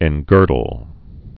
(ĕn-gûrdl)